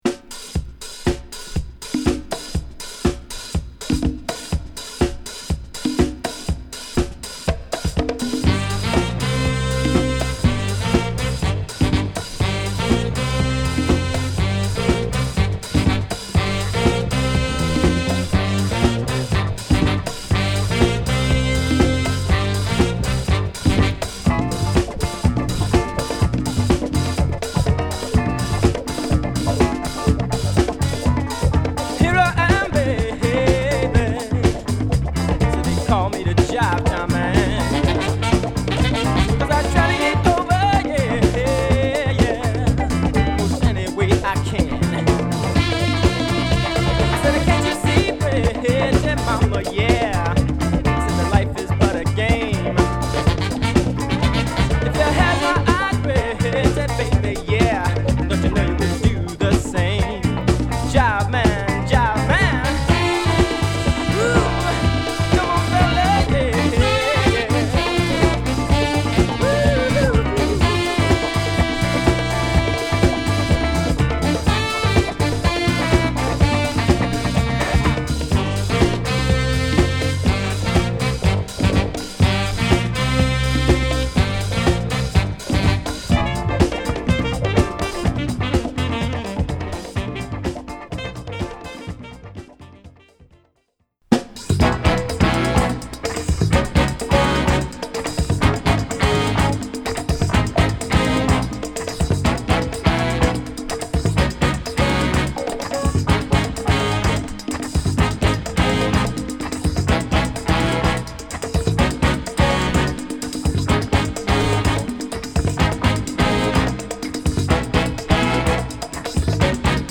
16歳から20歳までの若者達で結成されたファンクバンド